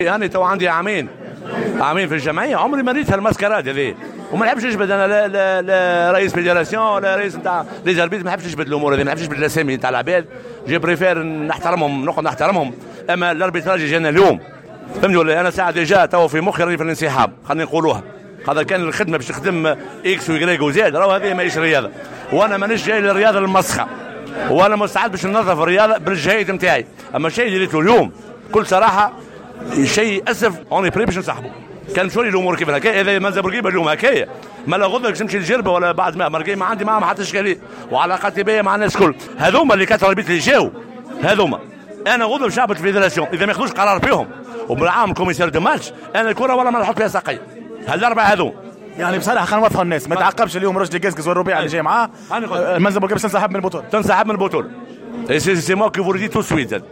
في تصريح لمراسلنا بالجهة